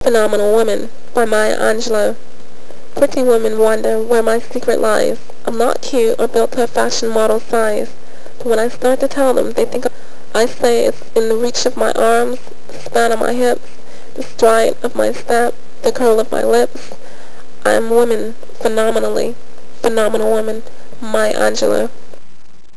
，在這之前，我先在這裡提供收集到的、經過訓練後的、三位小姐的聲音檔案